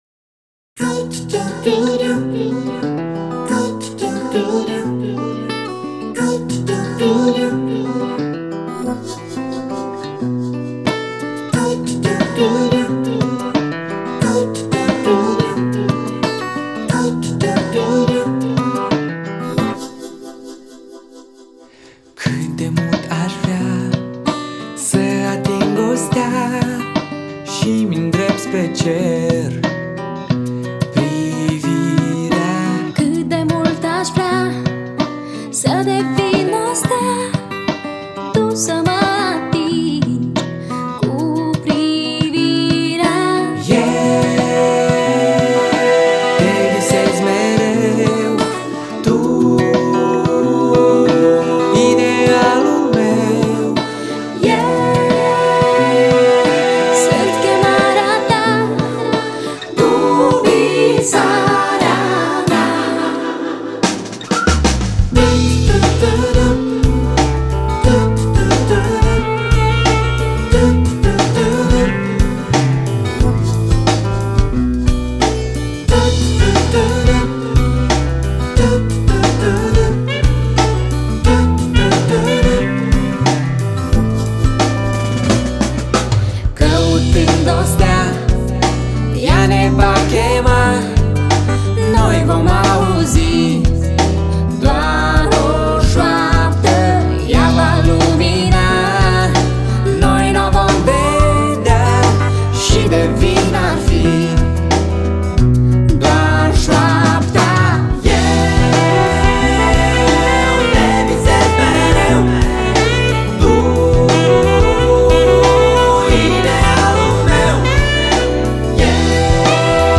chitară, vioară, voce
saxofon, clarinet, fluier, caval
baterie